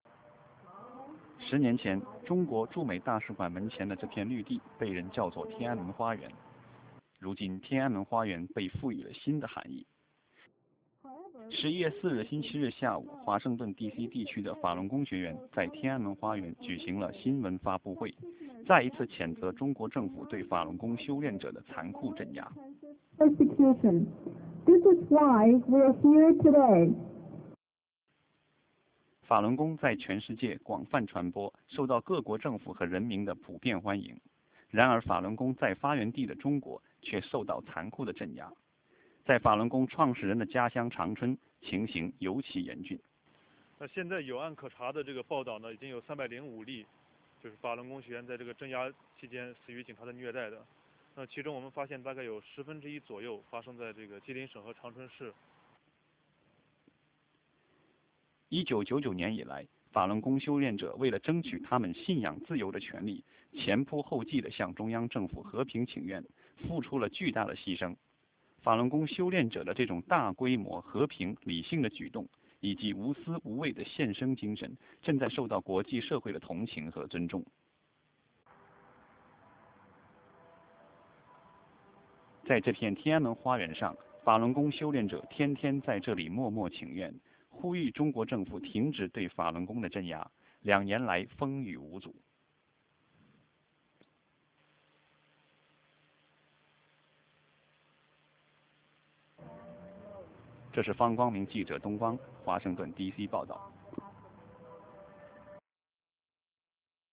DC_sunday_news_56k.ra